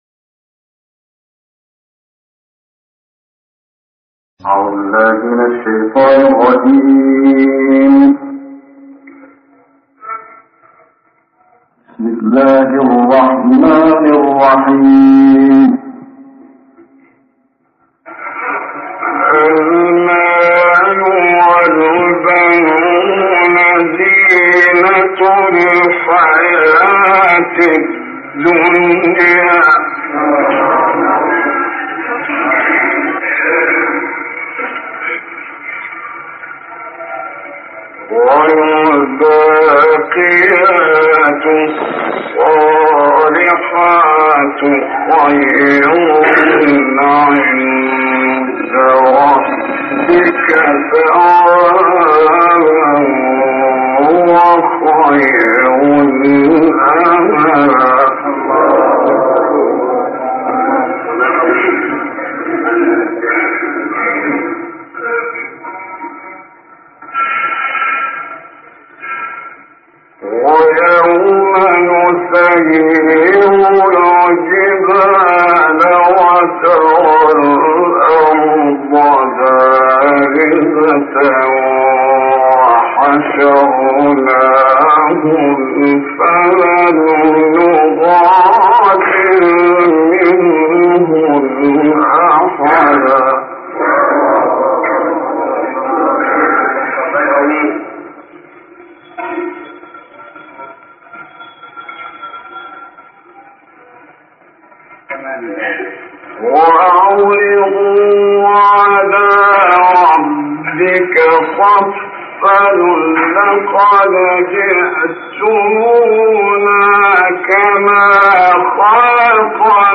تلاوت آیاتی از سوره کهف توسط استاد محمد رفعت